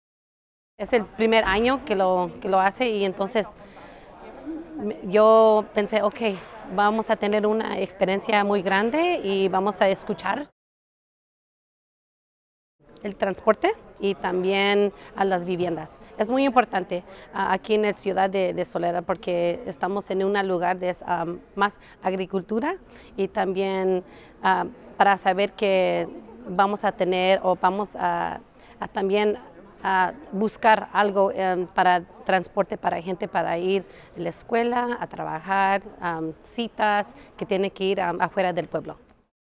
For radio news outlets who would like to air this story, the following links are soundbites of the State Legislative Issues Day in English and Spanish
Evarista Banuelos, Soledad City Councilmember (two cuts - Spanish) :40